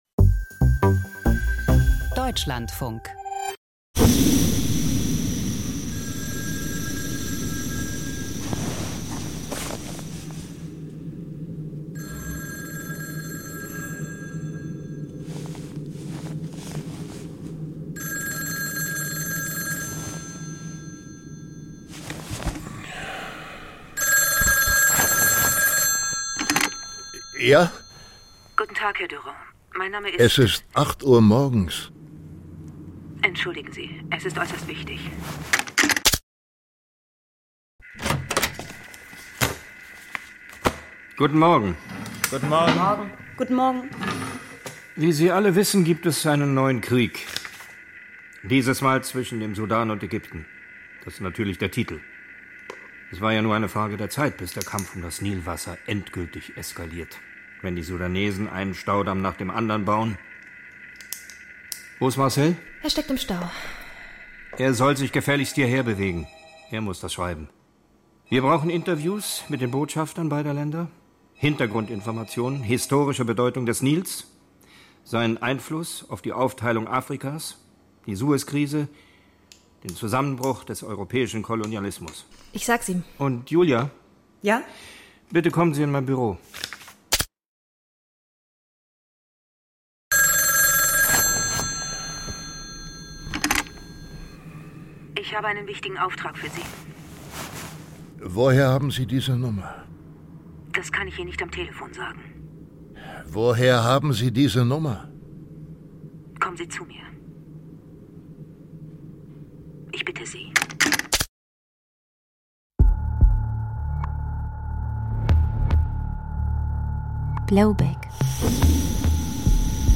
Aus dem Podcast Freispiel Podcast abonnieren Podcast hören Podcast Hörspiel (Freispiel) Unsere Freispiel-Produktionen finden Sie in unserem gemeinsamen Hörspiel-Podcast, den Sie hier...